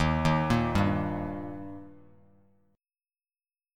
D# Chord
Listen to D# strummed